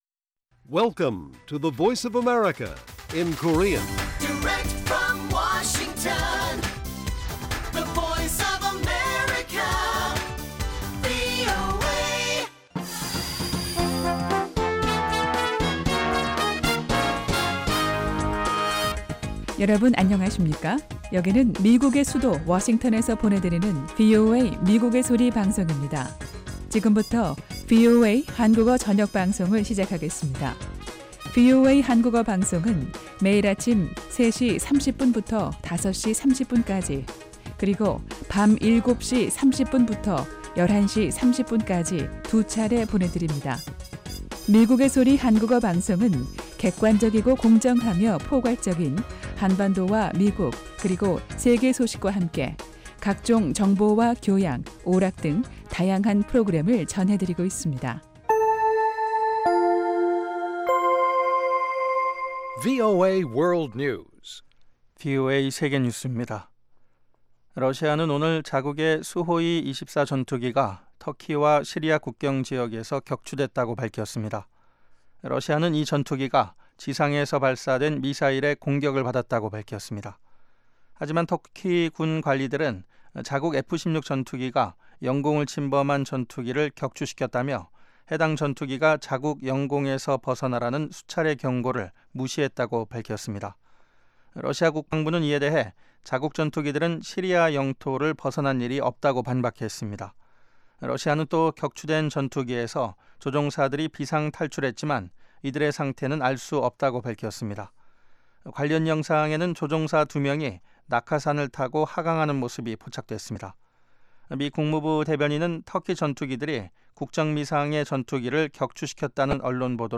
VOA 한국어 방송의 간판 뉴스 프로그램 '뉴스 투데이' 1부입니다. 한반도 시간 매일 오후 8:00 부터 9:00 까지, 평양시 오후 7:30 부터 8:30 까지 방송됩니다.